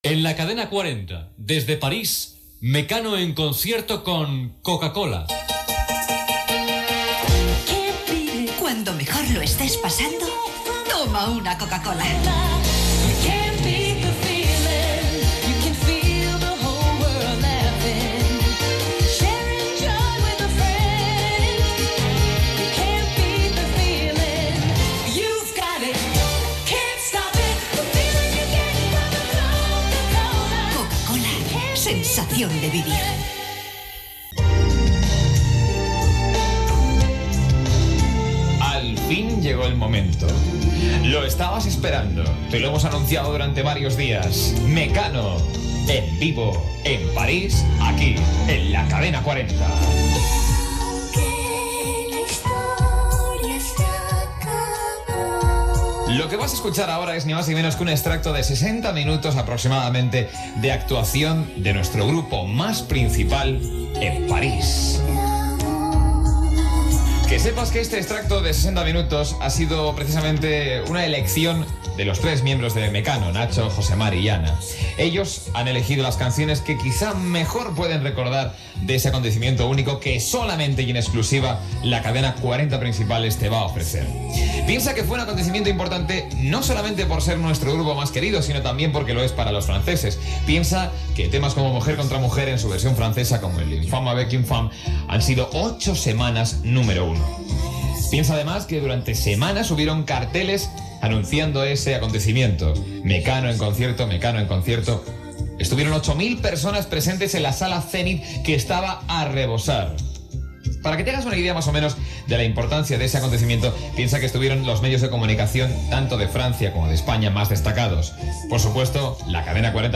Careta del programa, presentació de com va ser el concert i del primer tema
Musical